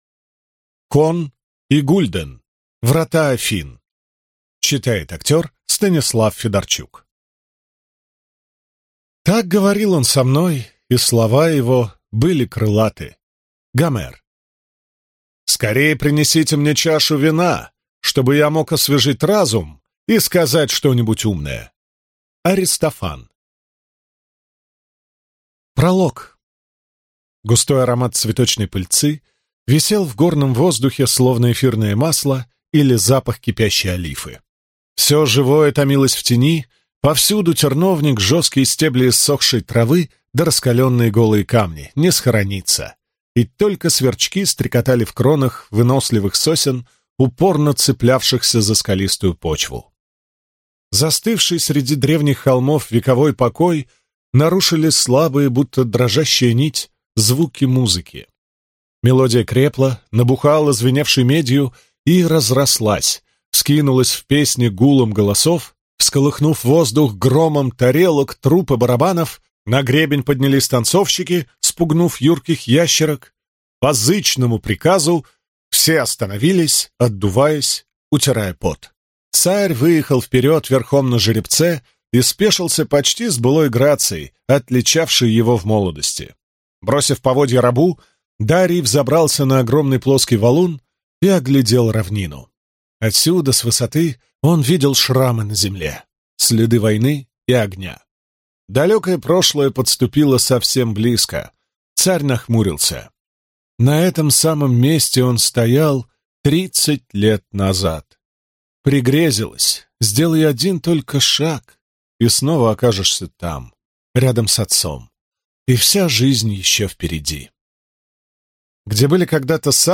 Аудиокнига Врата Афин | Библиотека аудиокниг